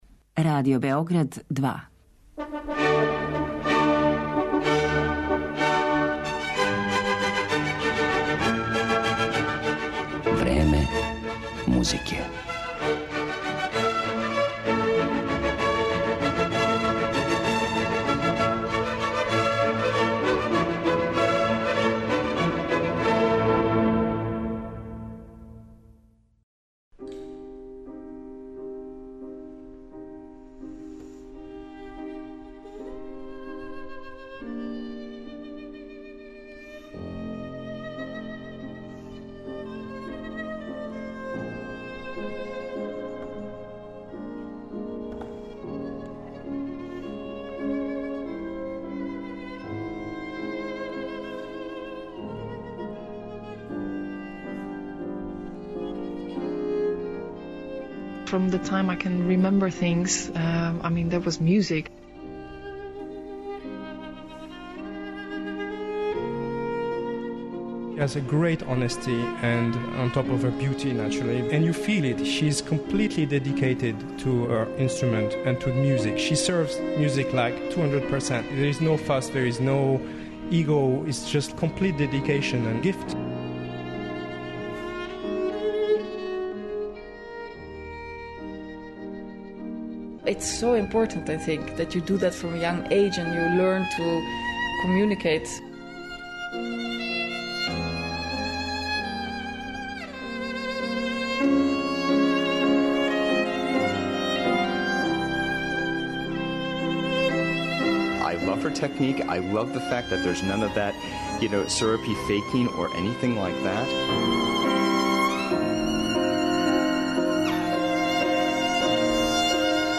Једној од највећих виолинисткиња млађе генерације, Јанин Јансен, посвећена је данашња емисија.
Ова славна холандска солисткиња, која осваја публику скоро романтичарском експресивношћу, изводиће композиције Антонија Вивалдија, Бенџамина Бритна, Мориса Равела и Јохана Себаcтијана Баха.